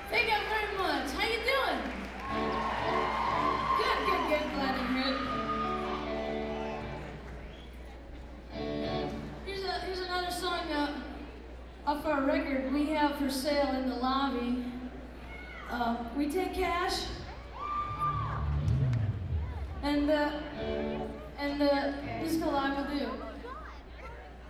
lifeblood: bootlegs: 1995-04-19: memorial auditorium - burlington, vermont (alternate recording 2)
04. talking with the crowd